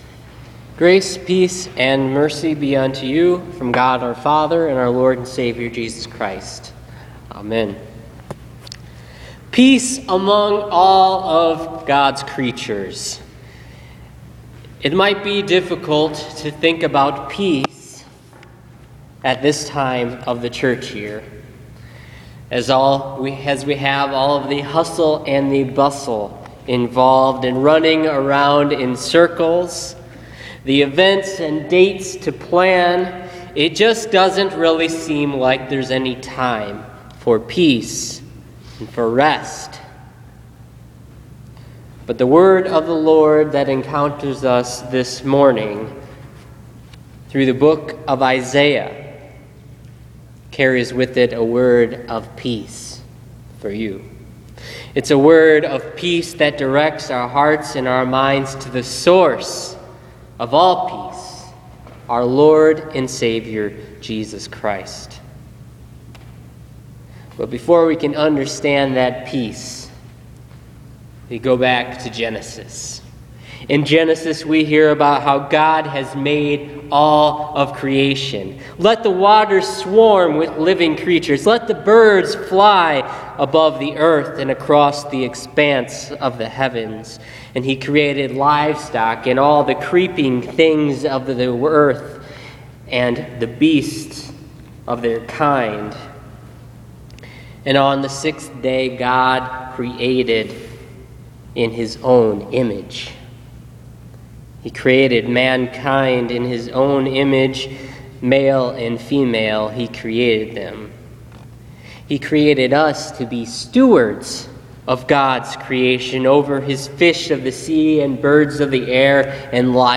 Listen to this week’s sermon on Isaiah 11:1-10 for the second week of Advent.